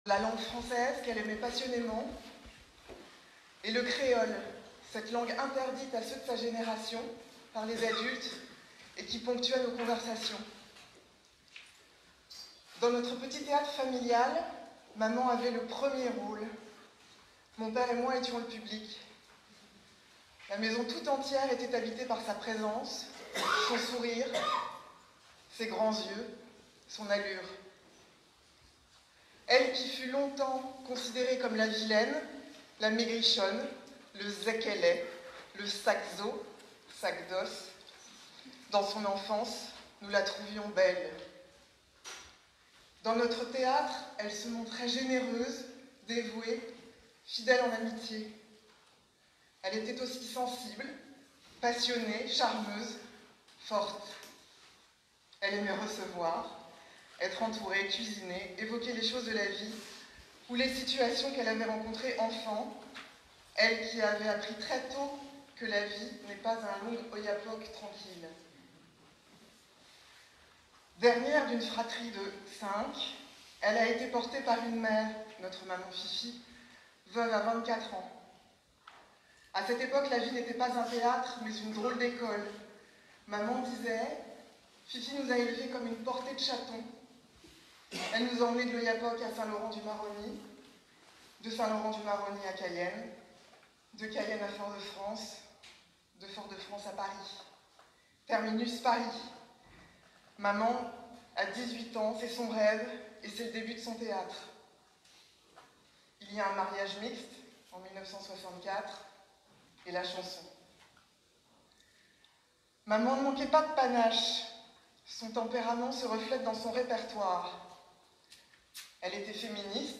Messe de dernier hommage à Josy Masse
La chanteuse guyanaise Josy Masse a été enterré en région parisienne ce jeudi 7 novembre. Une cérémonie émouvante pour rendre hommage à cette grande dame de la musique de Guyane, disparue à l'âge de 82 ans le 29 octobre. une équipe de Radio Mayouri Campus était sur place, nous vous proposons de revivre ce dernier hommage à Josy Mass dans son intégralité.